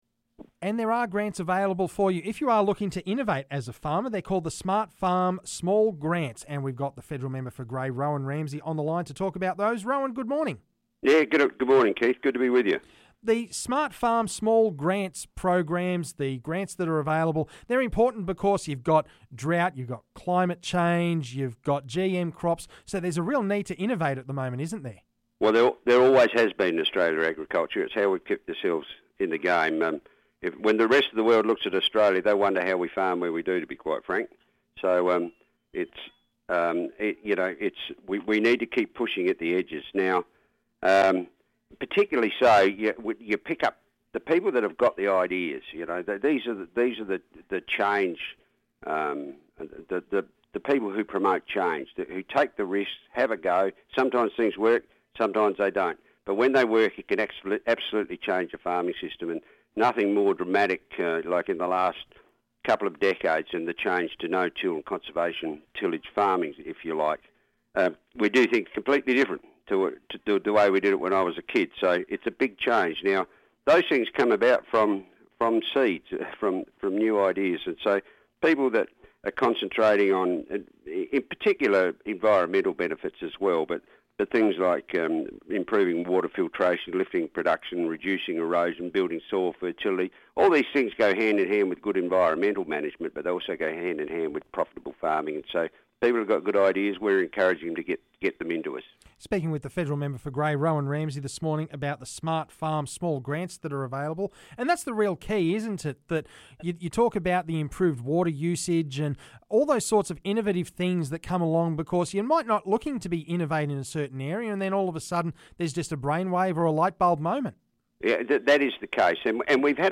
Federal Member for Grey Rowan Ramsey chats about funds that farmers have access to, and an update on Field Days too.